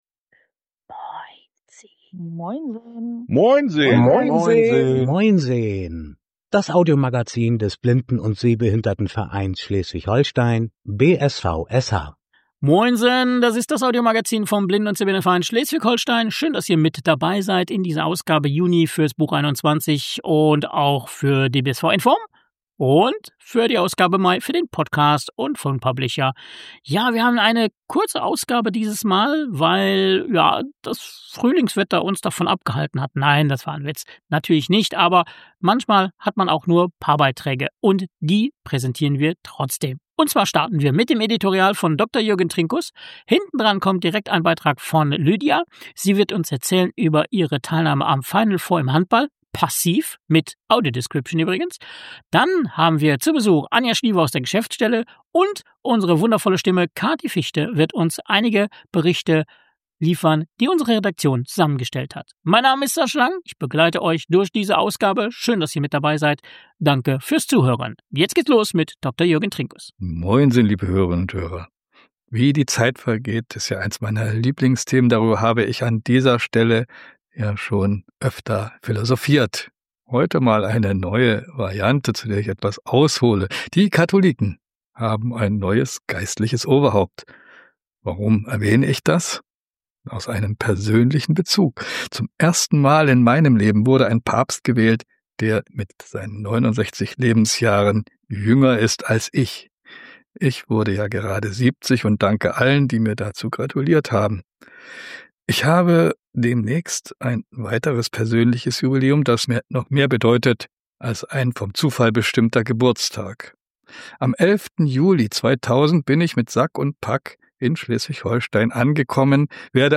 Beschreibung vor 10 Monaten Ausgabe Mai 2025 Willkommen zur kompakten, aber inhaltsreichen Frühsommer-Ausgabe unseres Hörmagazins!